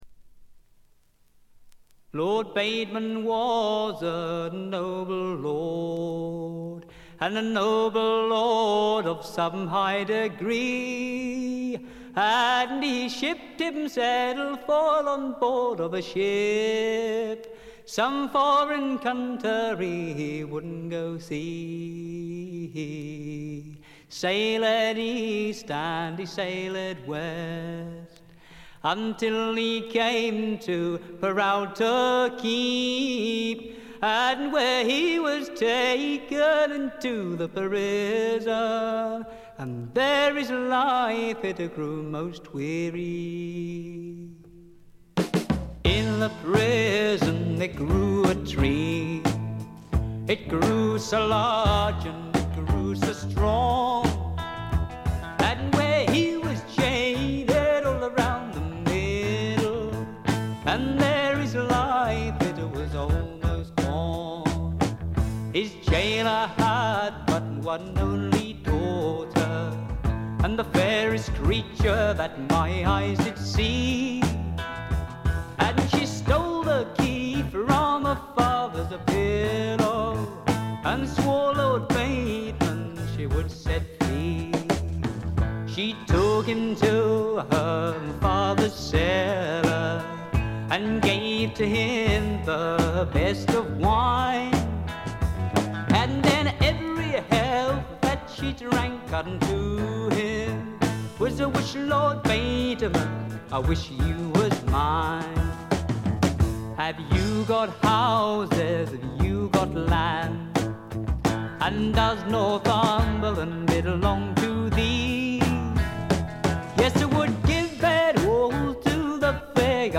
微細なチリプチ少々。
試聴曲は現品からの取り込み音源です。
Tambourine